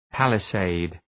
{,pælı’seıd}